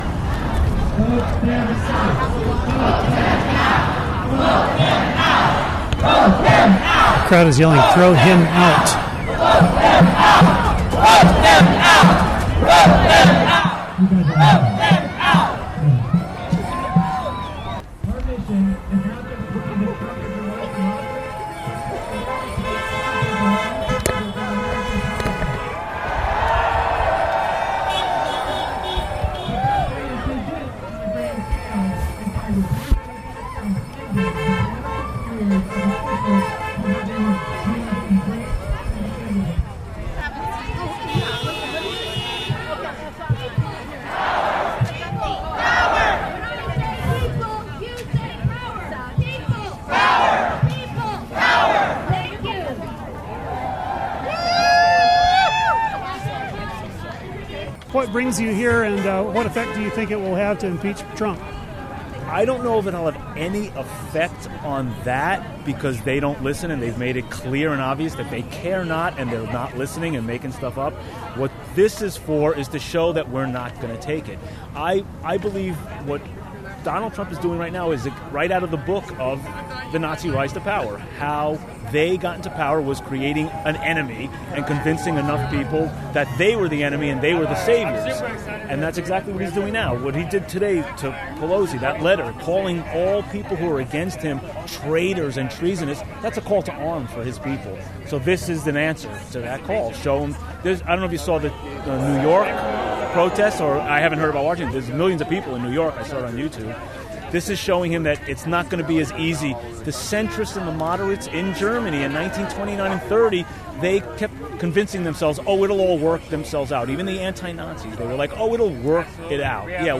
interviewed some of the participants.